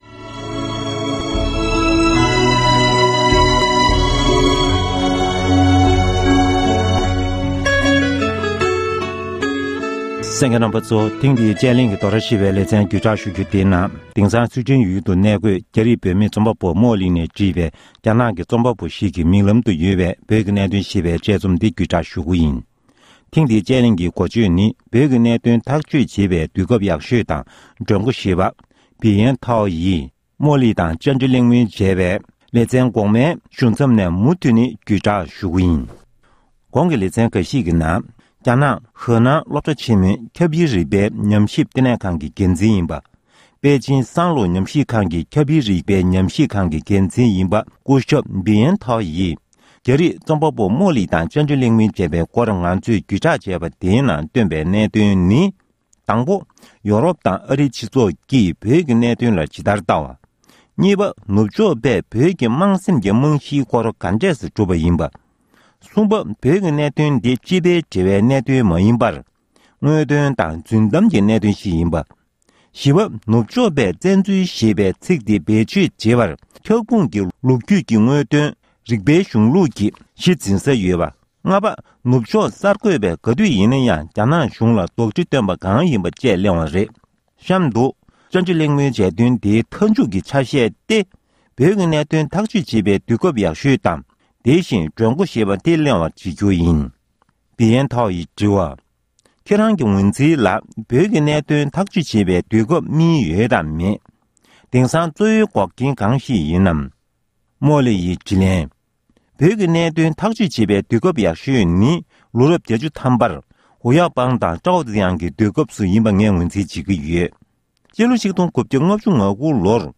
བཅའ་དྲི་གླེང་མོལ་བྱས་དོན།